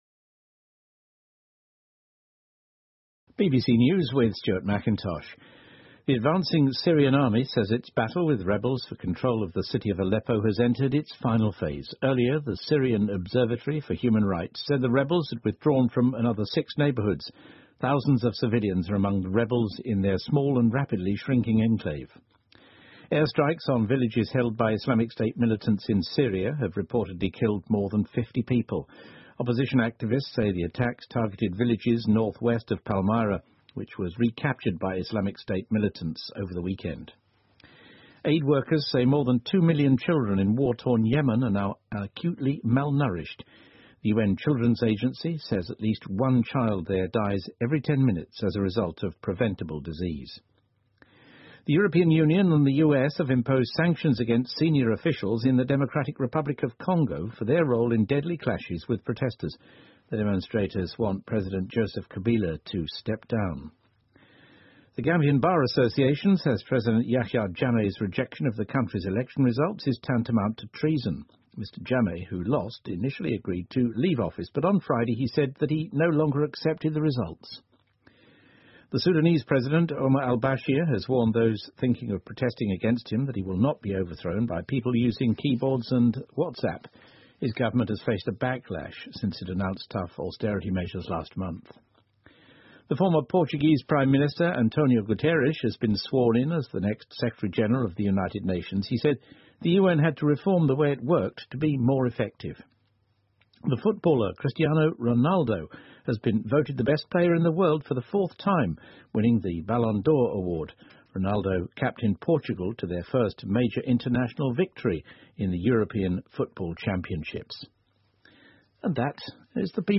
英国新闻听力 葡萄牙前总理就任联合国秘书长 听力文件下载—在线英语听力室